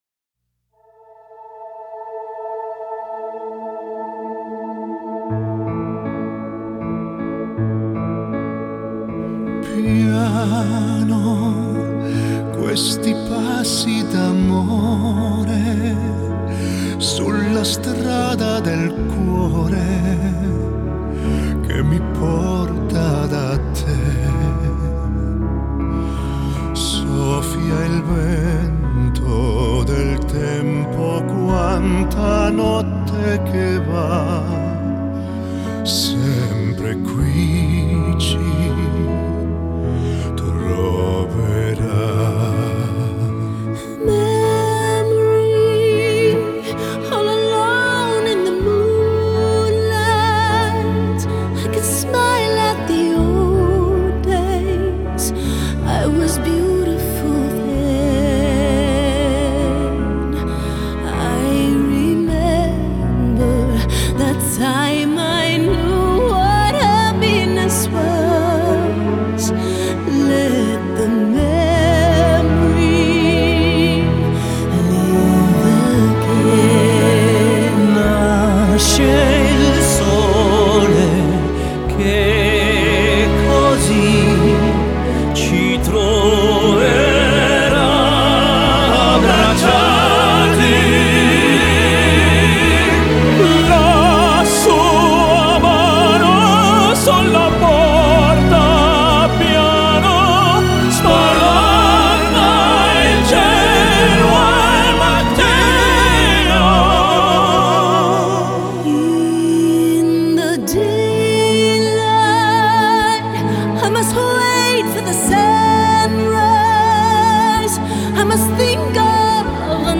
Genre: Pop, Crossover, Musical